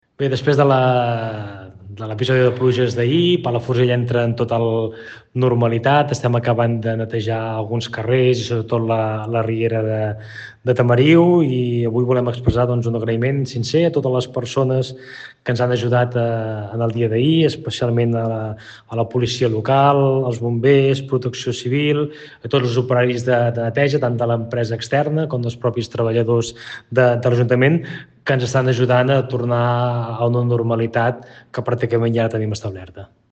Palafrugell, les principals afectacions van arribar a la riera de Tamariu, que és on s’estan concentrant gran part dels esforços de neteja i restabliment durant el dia d’avui. Són declaracions de l’alcalde accidental del municipi, Marc Piferrer, qui també vol agrair la tasca dels serveis i cossos que han col·laborat durant aquests dies.